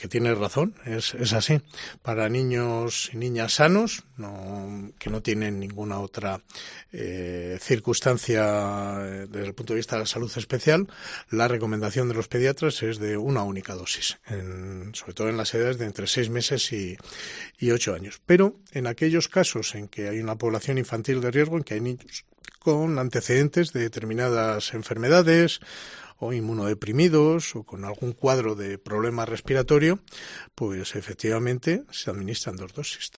José Luis Sanz Merino, delegado territorial de la Junta de Castilla y León en Segovia